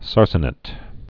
(särsnĭt)